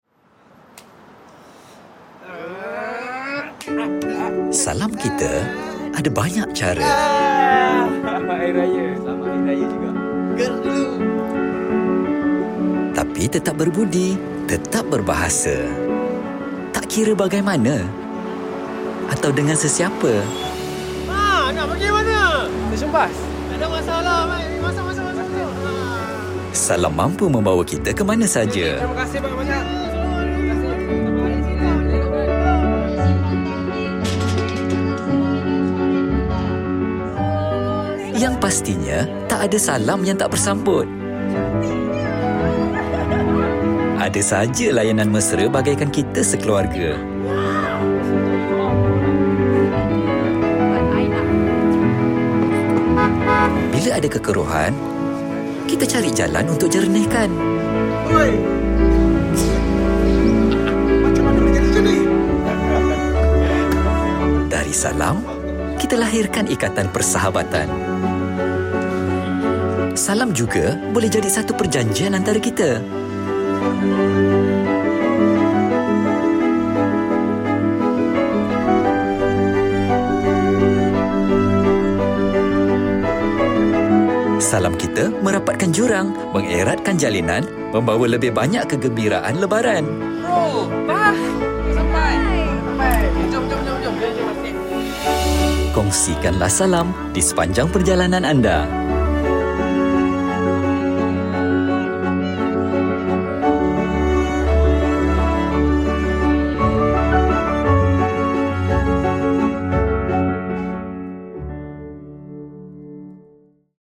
Showreel
Fresh, young, multi-characteristics & versatile real male voice in town.